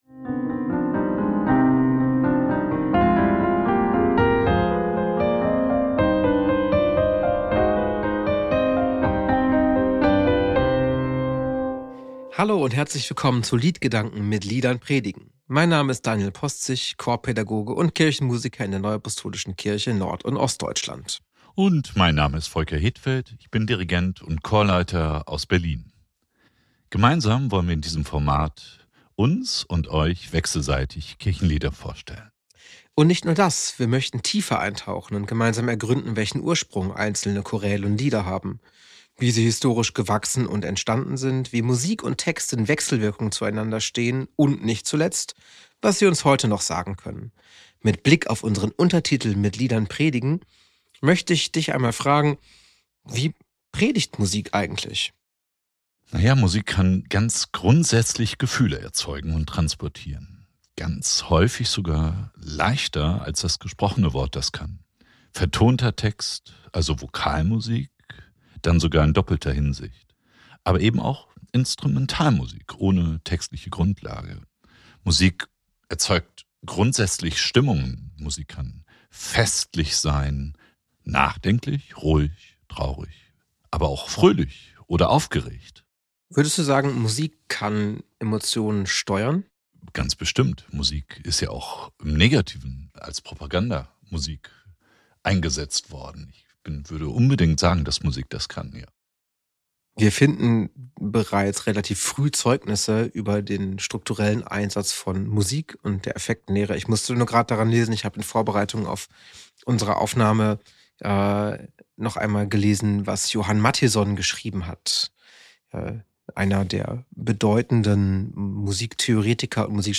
Die beiden Moderatoren